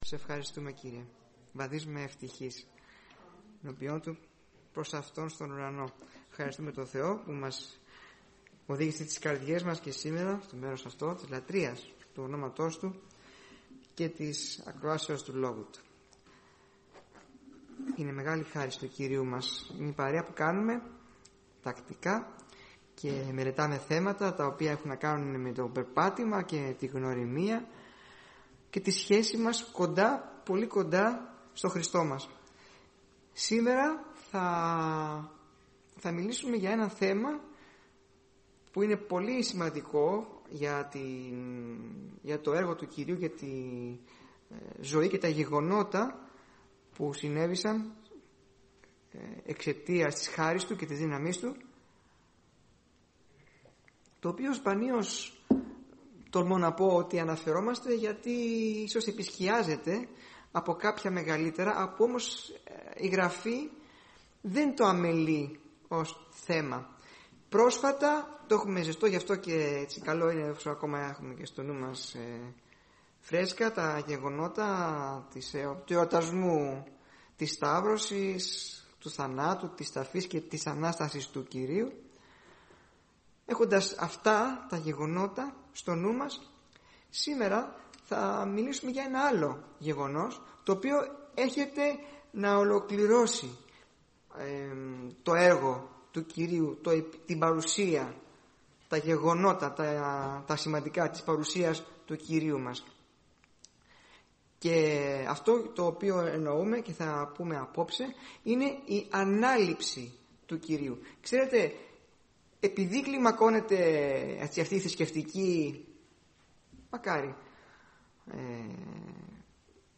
Μαθήματα Θέματα